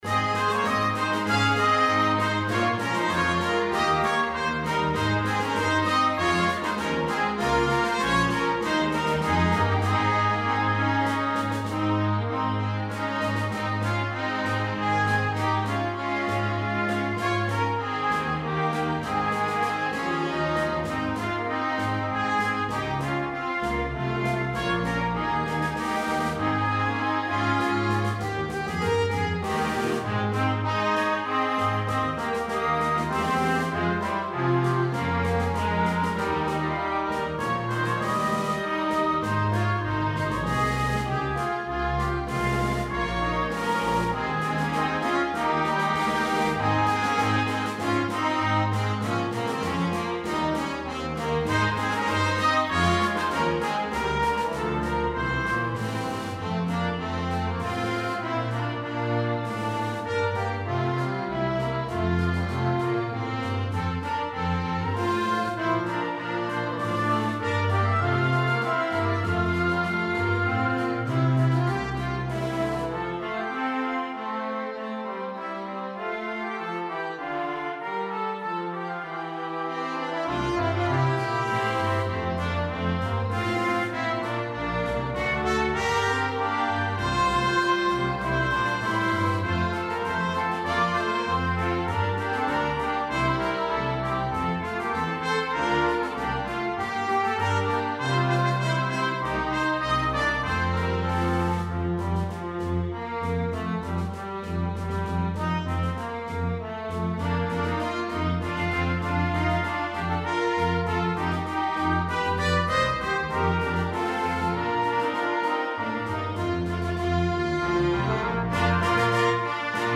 This is a triumphant and energetic setting of the hymn.
Arranged in 4 parts, fully orchestrated.